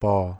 중성조 (Mid)비장popo
몽어 po 발음